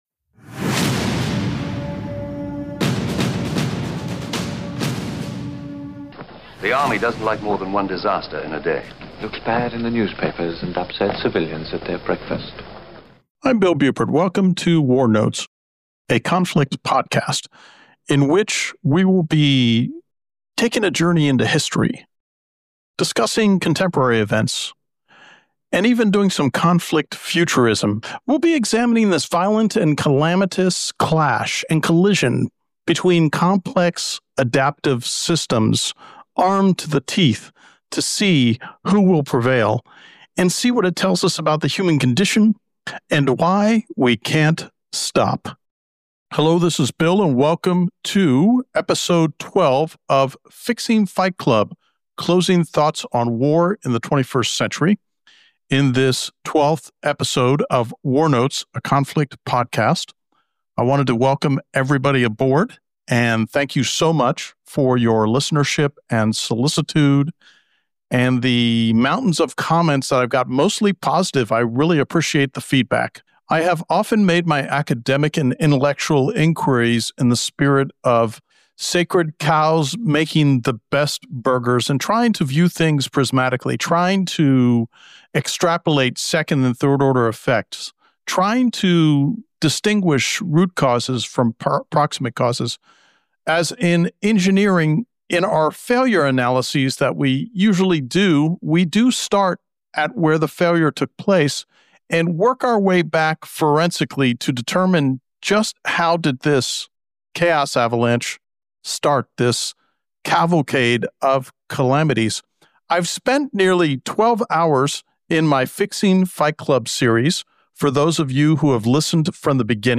Effective 20 June 2025, I did a major improved revision on the sound quality for all my WarNotes episodes retroactively thanks to the technology at Podsworth.